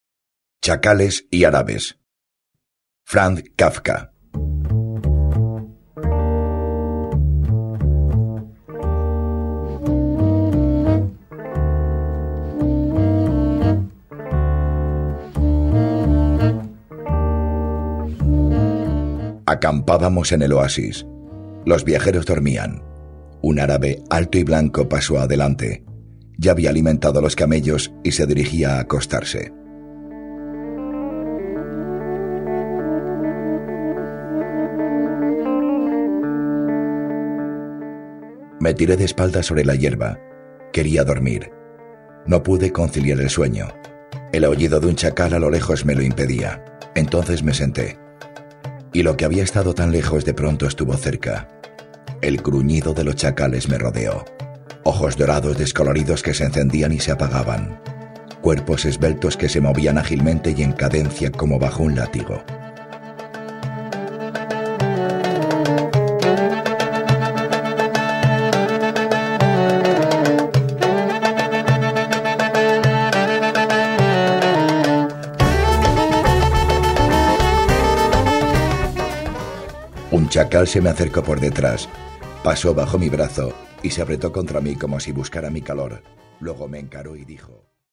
Audiolibro: De chacales y árabes
Música: Blancheneige Bazaar Orchestra (cc:by-sa)